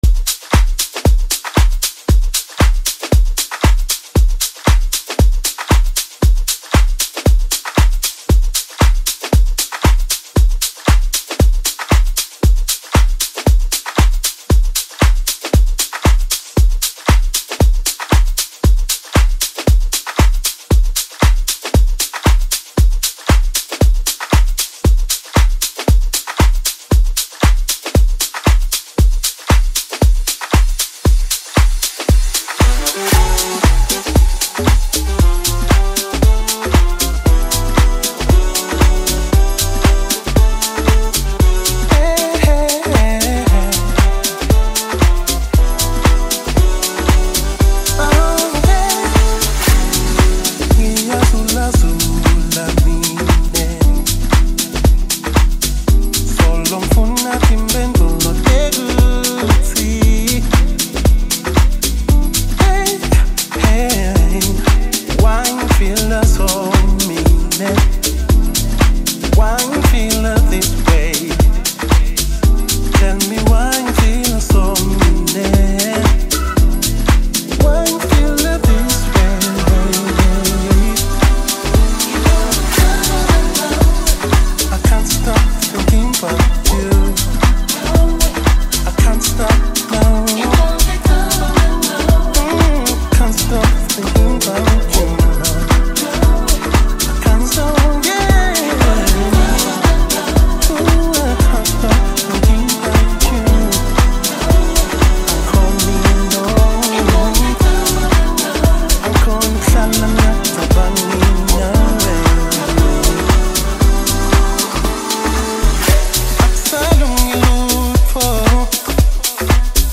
” offering smooth vocals, meaningful lyrics, rich production